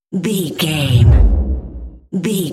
Whoosh deep
Sound Effects
dark
intense
whoosh